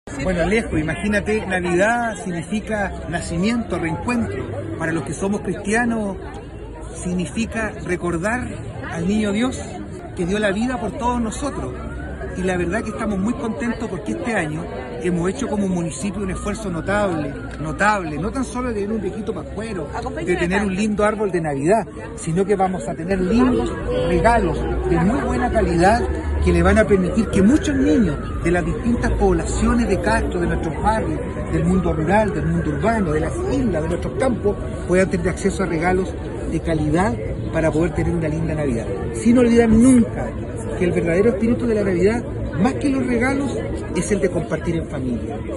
CUNA-ALCALDE-CARAVANA-NAVIDENA.mp3